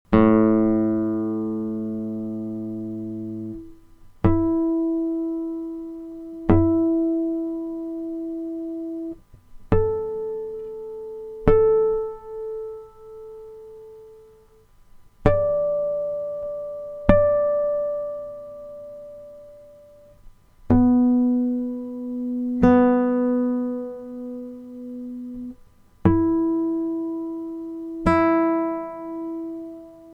Das Stimmen mit Flageolett-Tönen und Powerchords
Flageolett Töne klingen recht hoch und flötenartig (das ist wohl auch die ursprüngliche Bedeutung des Worts).
Die leere hohe E-Saite muss genauso klingen, wie der Flag. Ton am 7. Bund A-Saite: